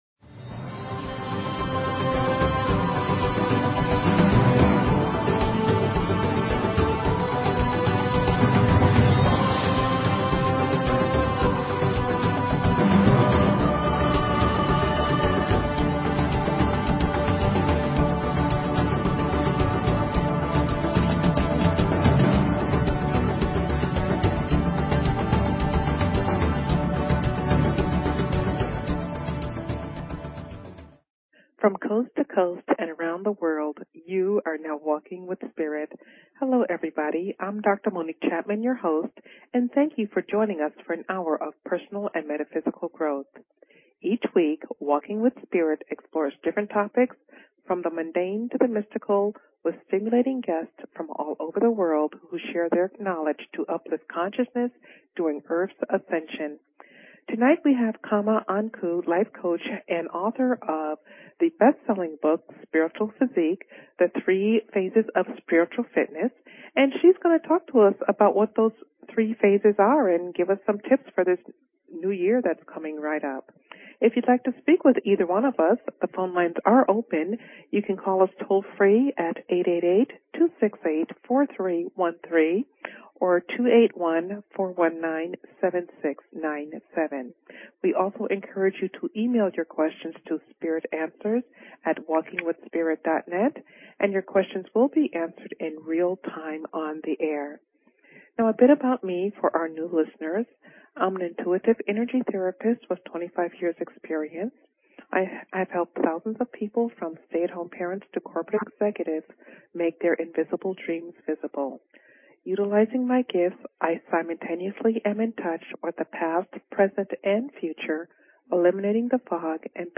Talk Show Episode, Audio Podcast, Walking_with_Spirit and Courtesy of BBS Radio on , show guests , about , categorized as
Interview